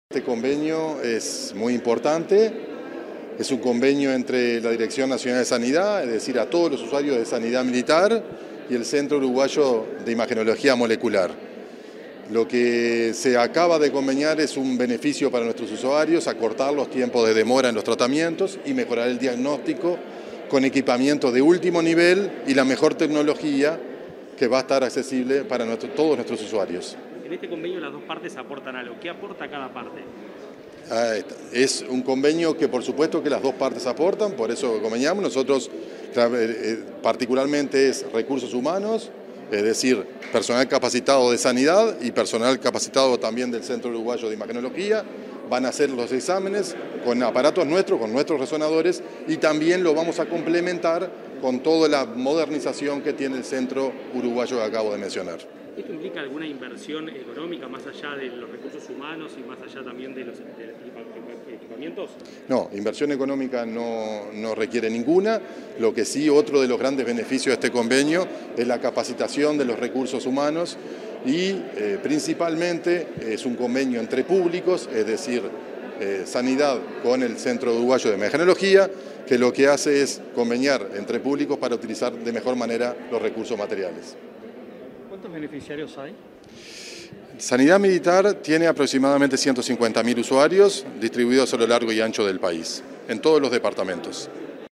Declaraciones del director nacional de Sanidad de las Fuerzas Armadas, Hugo Rebollo
Declaraciones del director nacional de Sanidad de las Fuerzas Armadas, Hugo Rebollo 16/02/2023 Compartir Facebook X Copiar enlace WhatsApp LinkedIn Tras la suscripción de un convenio entre el Cudim y Sanidad Militar, este 16 de febrero, el director nacional de Sanidad de las Fuerzas Armadas, Hugo Rebollo, realizó declaraciones a la prensa.